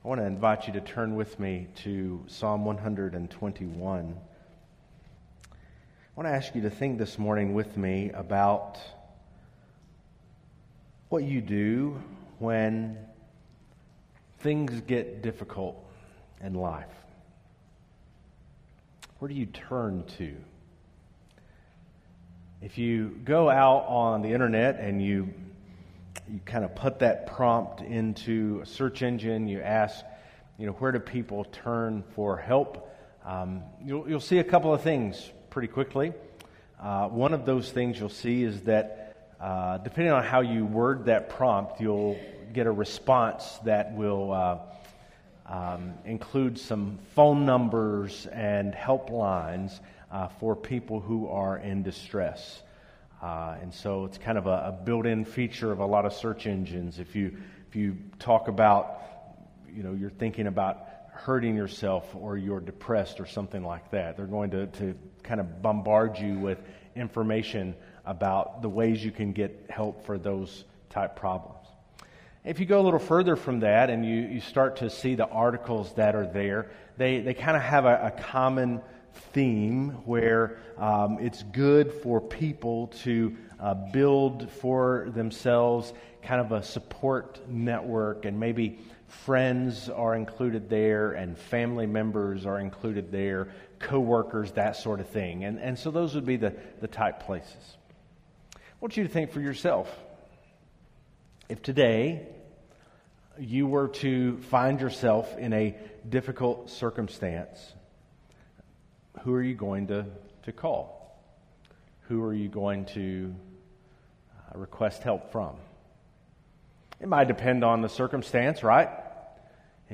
Play Rate Listened List Bookmark Get this podcast via API From The Podcast Enjoy sermons from the pulpit of First Baptist Icard, a Southern Baptist Church located in the foothills of North Carolina.